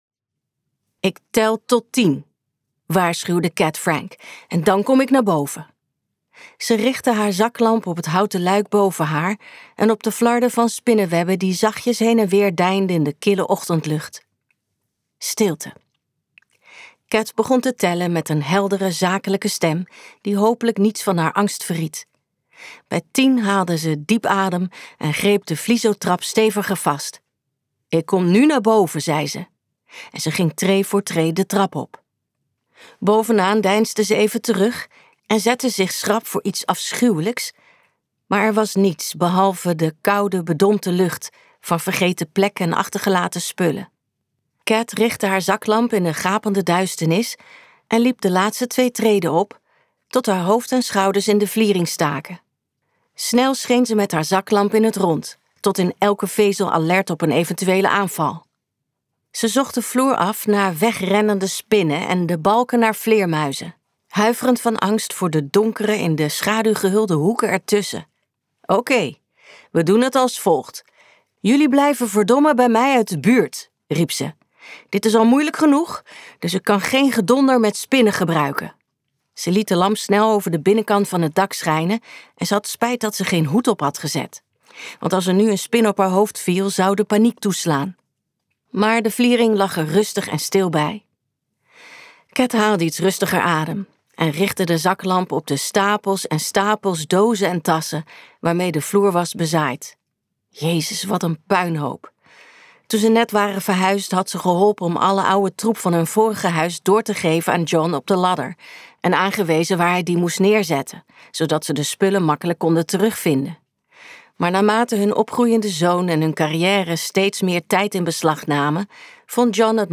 Ambo|Anthos uitgevers - Dood spoor luisterboek